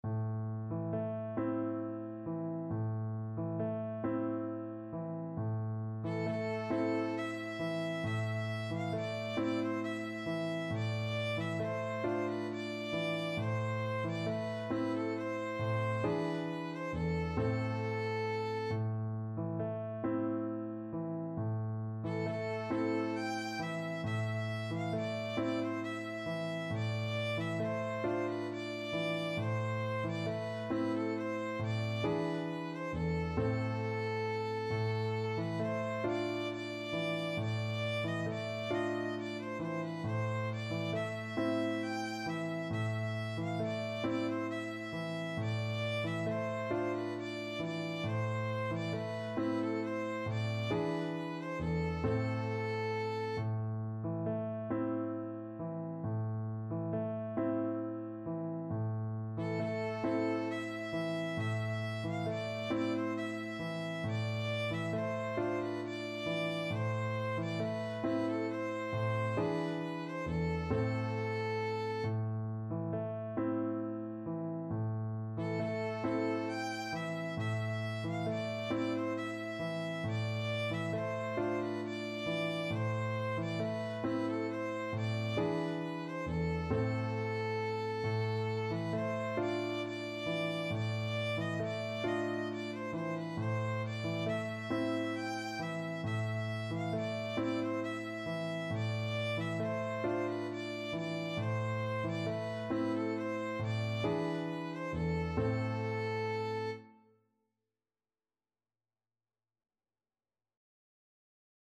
Violin
Gently rocking .=c.45
A minor (Sounding Pitch) (View more A minor Music for Violin )
Classical (View more Classical Violin Music)
Turkish
sari_gelin_VLN.mp3